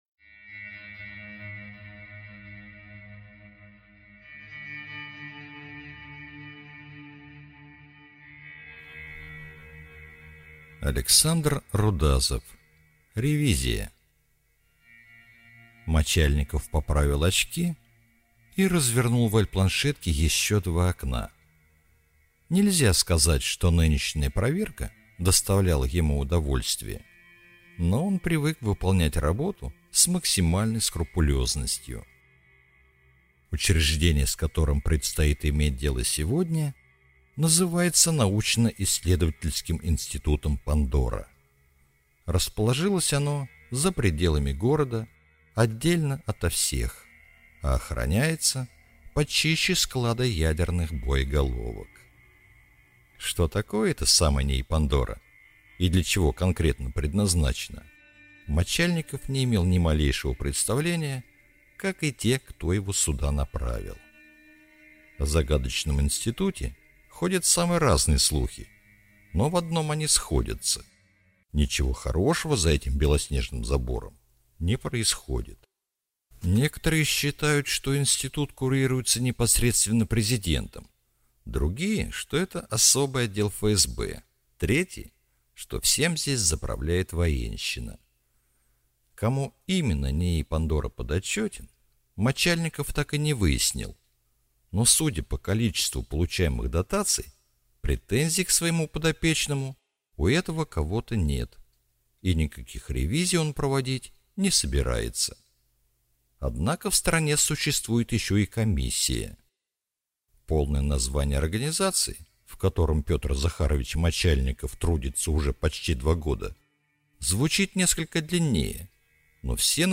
Аудиокнига Ревизия | Библиотека аудиокниг